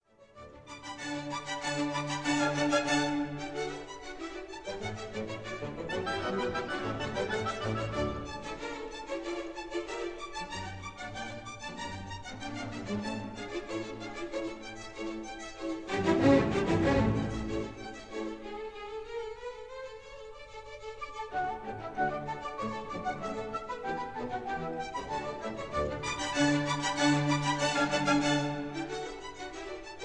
in D major